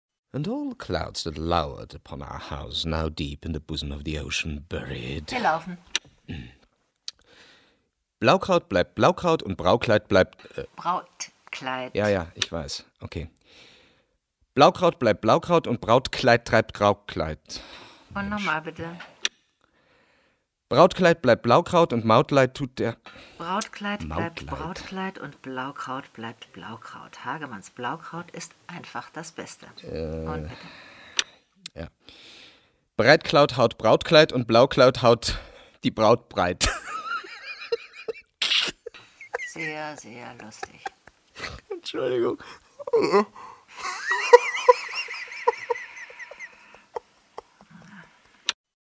Diese Wurfsendungen führen direkt ins Studio, und zwar zu Tonaufnahmen für verschiedene Werbespots.